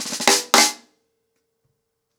TheQuest-110BPM.9.wav